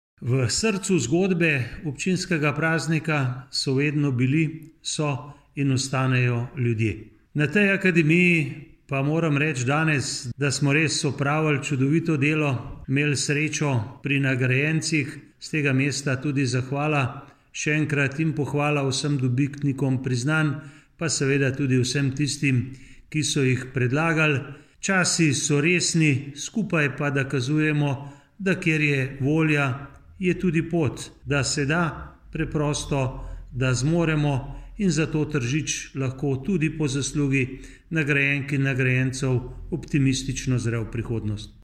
izjava_mag.borutsajovcizupanobcinetrzic_obcinskipraznik.mp3 (1,0MB)